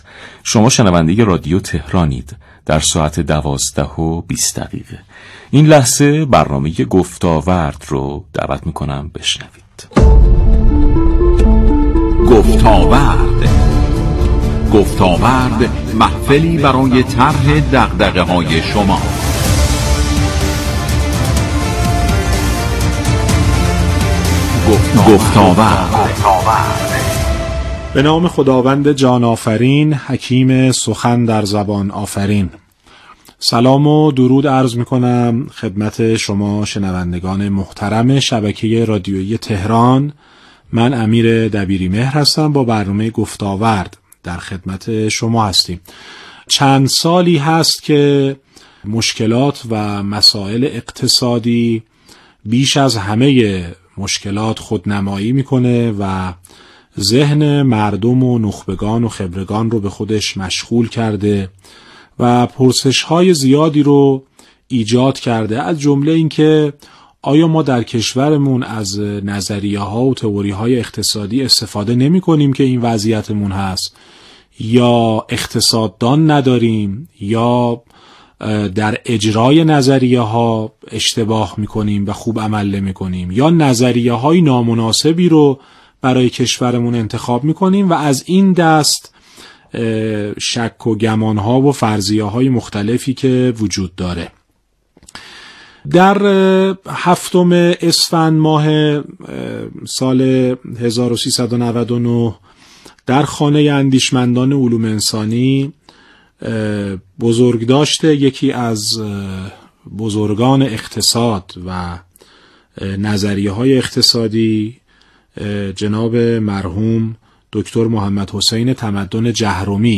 مشروح این گفتگو را در لینکهای زیر بشنوید.